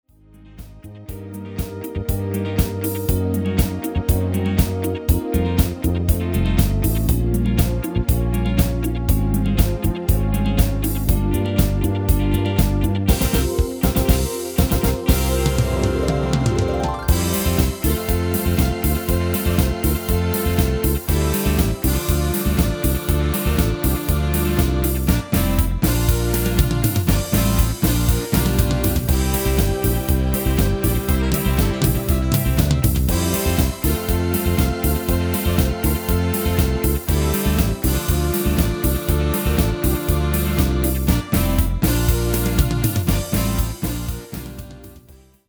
MP3 backing track arranged in the style of:
Genre: Nederlands amusement / volks
Key: F#m/Gm
File type: 44.1KHz, 16bit, Stereo
Demo's played are recordings from our digital arrangements.